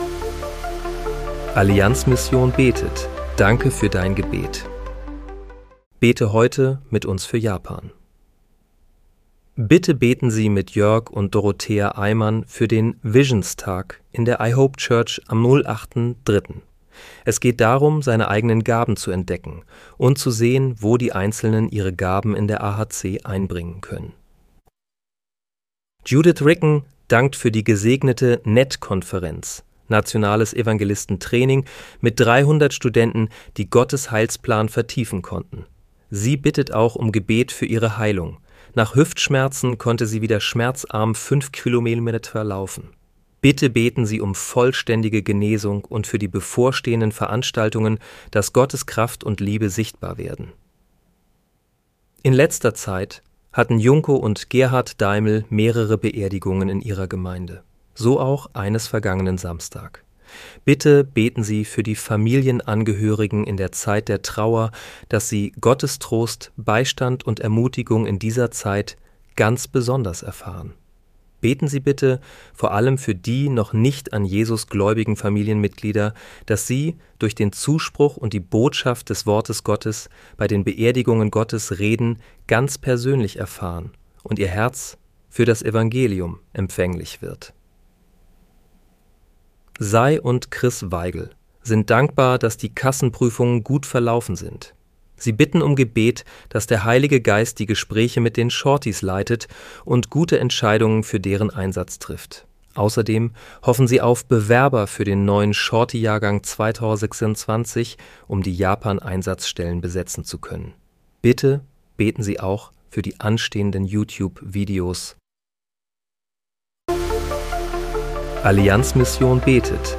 Bete am 07. März 2026 mit uns für Japan. (KI-generiert mit der